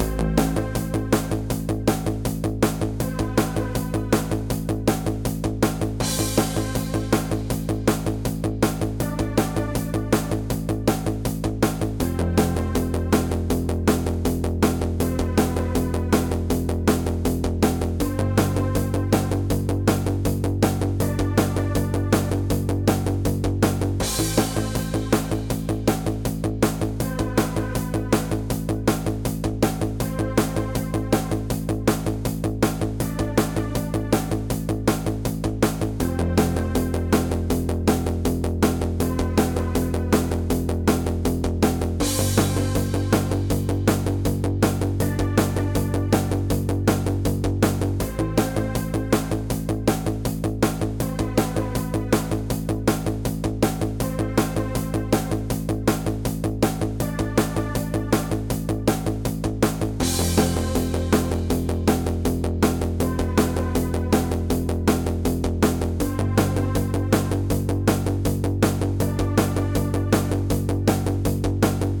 Retrowave game music